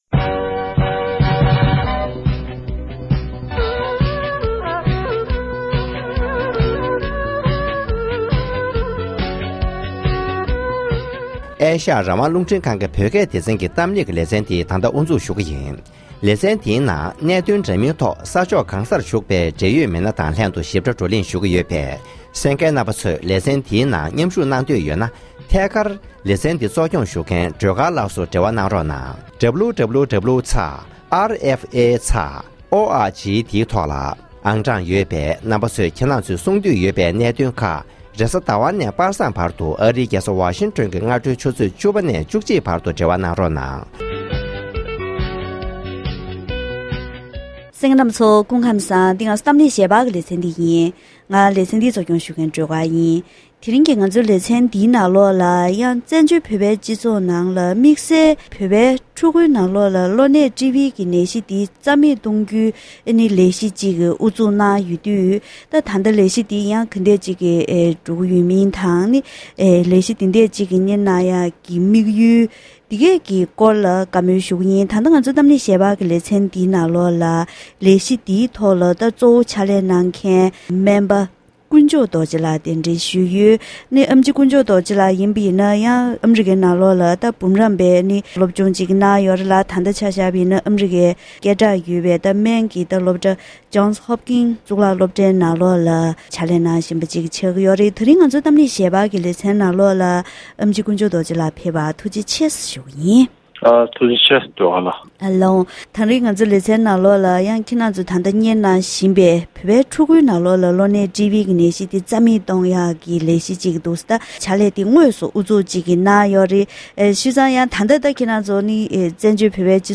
༄༅༎དེ་རིང་གི་གཏམ་གླེང་ཞལ་པར་ལེ་ཚན་ནང་བཙན་བྱོལ་གྱི་བོད་ཕྲུག་ནང་གློ་ནད་ཊི་སྦི་ནད་འདི་རྩ་མེད་གཏོང་རྒྱུའི་ལས་གཞི་དེ་ད་ལྟ་བཙན་བྱོལ་སྤྱི་ཚོགས་ནང་འགོ་བཙུགས་ཡོད་པས་ལས་དོན་ཇི་ལྟར་འགྲོ་བཞིན་ཡོད་མེད་སྐོར་དང་།